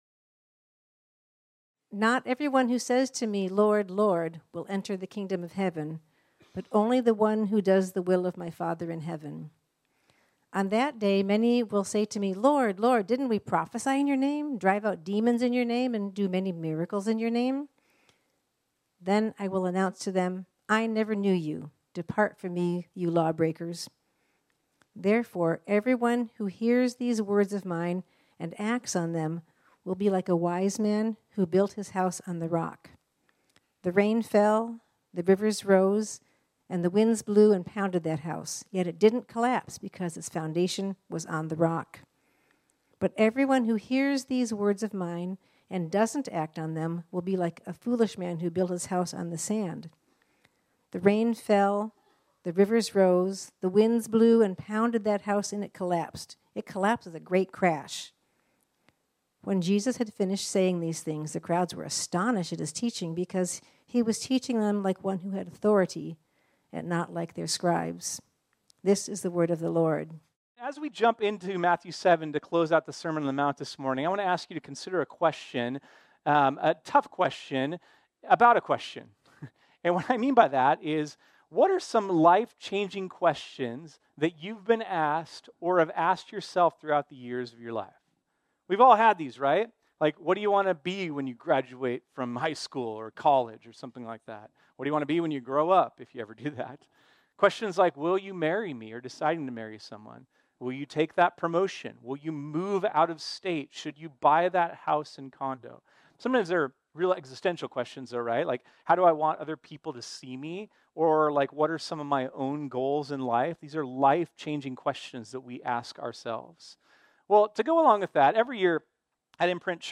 This sermon was originally preached on Sunday, March 24, 2024.